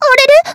taiko-soft-hitwhistle.wav